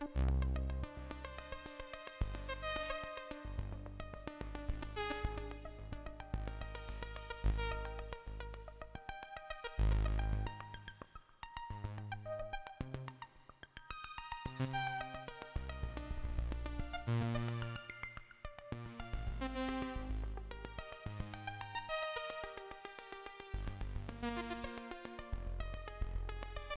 i dont feel like going through all my presets now but here’s six i just did. some are more similar just gritty fm brassy stuff, but i there is a nice quality to the synth where you can get high notes that are clean woody almost digital dx fm sounding but organic while the low notes and chords are fuzzy and warm at the same time. I’ll try to find some of those later, but these are just some i was able to record quickly just now. sorry about the volume differences, i forgot to normalize a couple. also excuse my playing, i suck